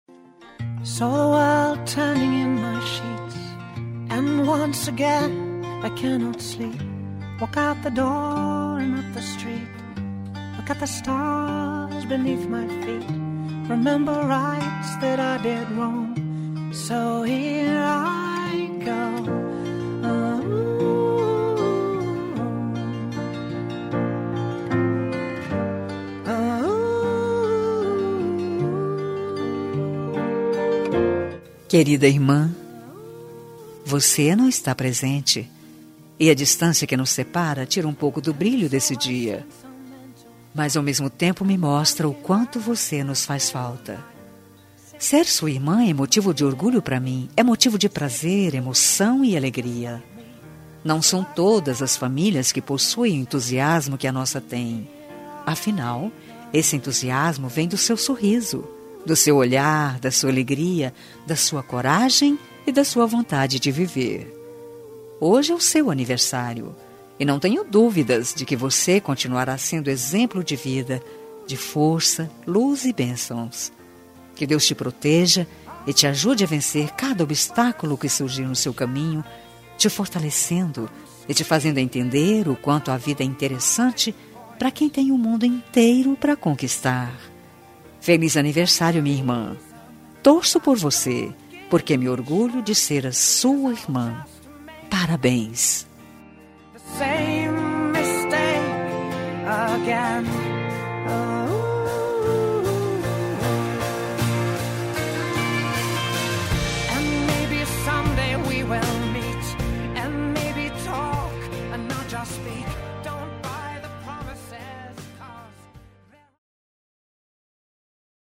Telemensagem de Aniversário de Irmã – Voz Feminina – Cód: 1652 – Distante